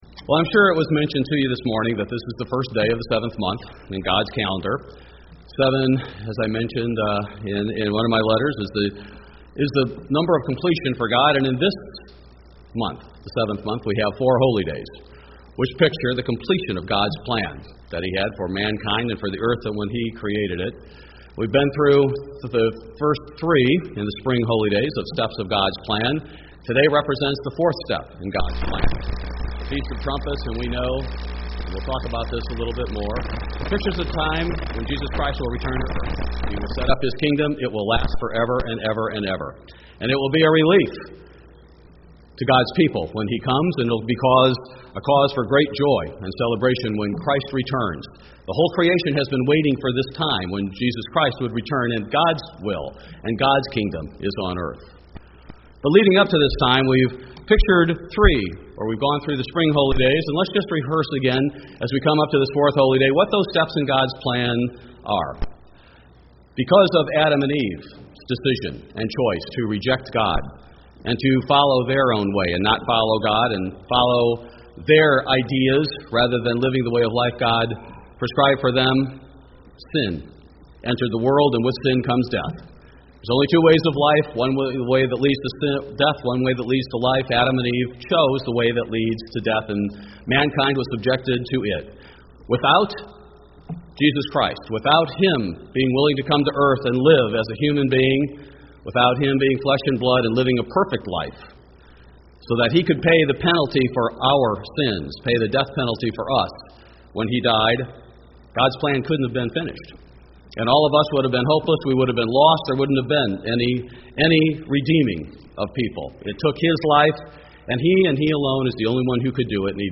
How does the Feast of Trumpets relate to the seven seals and seven trumpets described in Revelation? This message was given on the Feast of Trumpets.